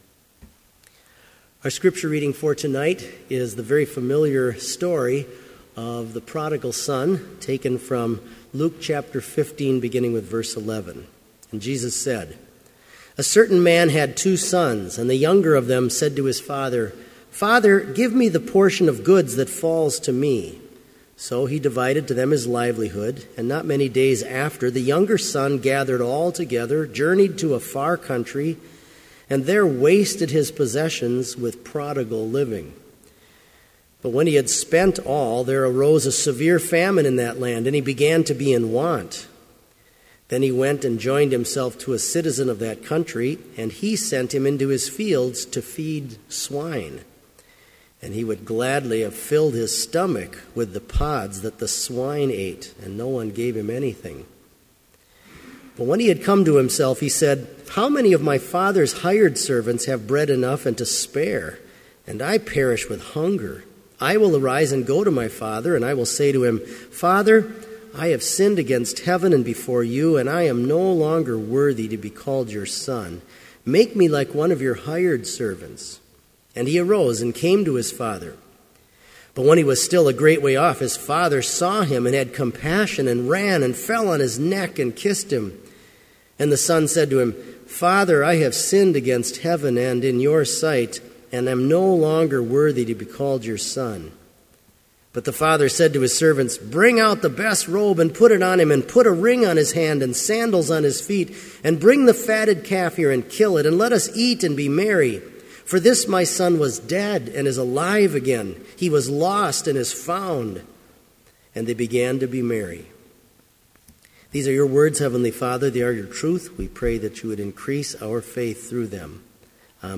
Complete service audio for Evening Vespers - February 4, 2015